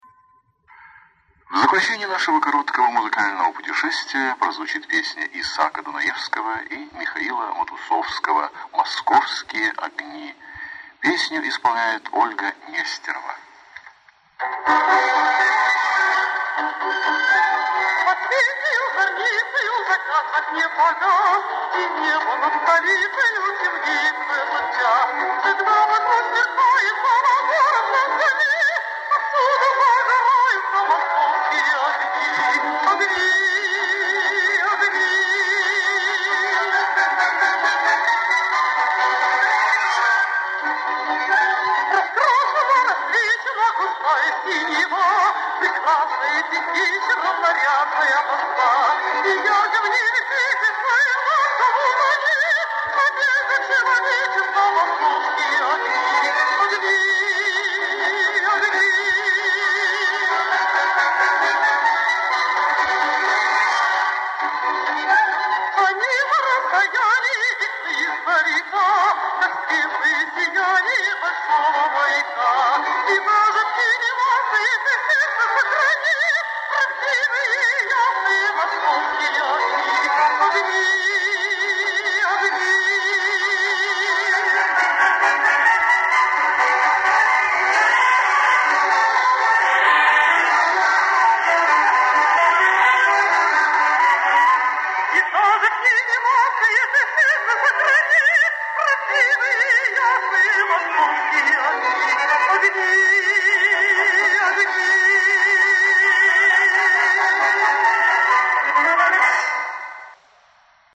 Источник аудиокассета